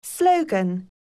듣기     /     영국 [slóugən]